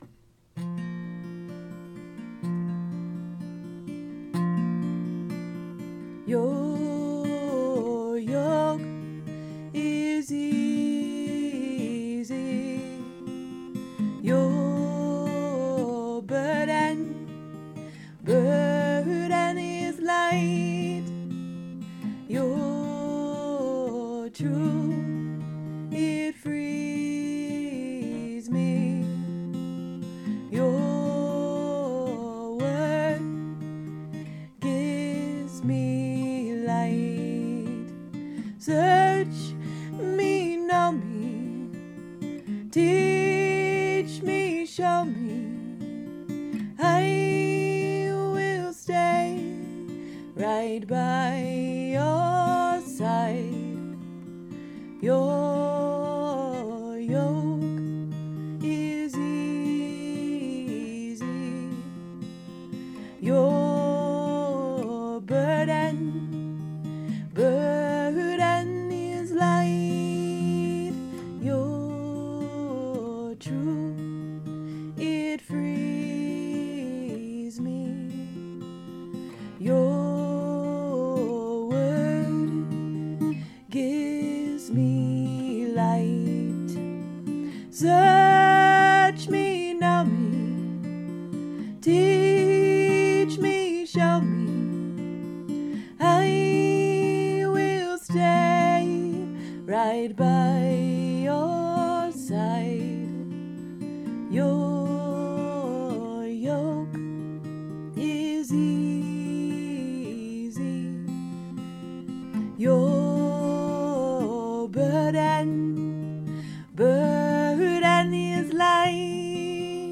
This is my "calm down, focus, listen, time to pray" song.